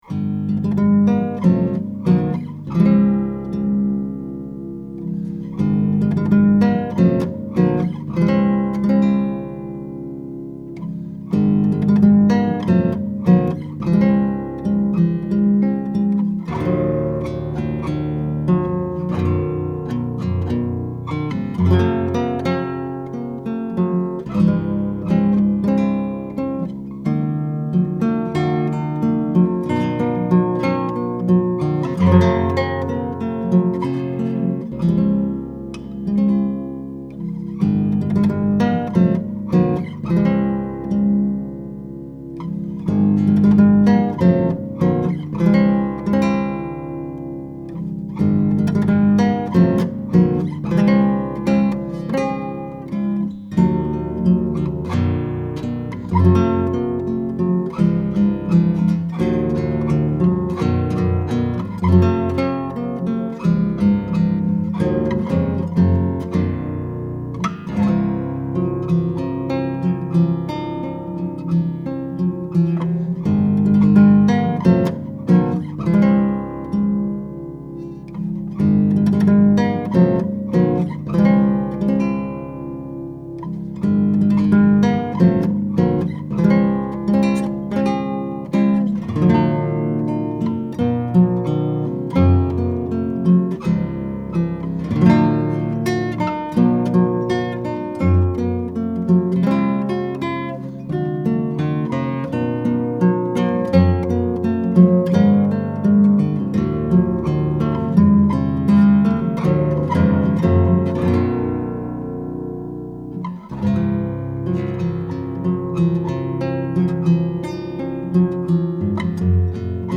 10-String Guitar
I have the guitar tuned in Romantic tuning, a standard 10-string tuning where 1-6 is normal, and 7-10 descend step-wise: 7=D, 8=C, 9=B, 10 =A. Strings By Mail has a great selection of 10-string sets and can put together custom sets for you at a discount over individual strings.
This is straight, pure signal with no additional EQ or effects.
(Traditional Celtic)